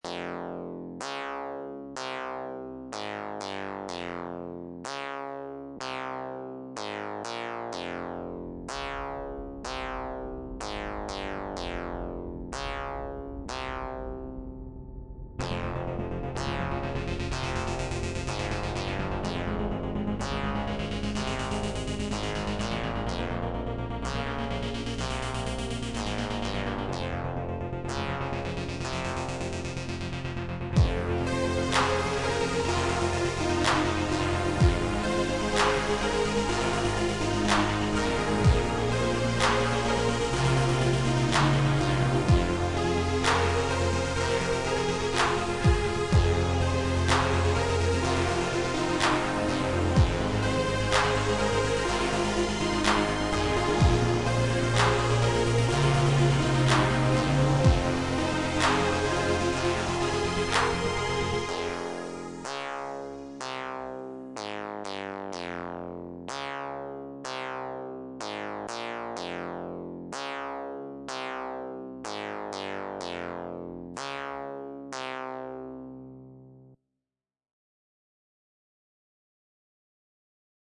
Inspired by Dark 80s synth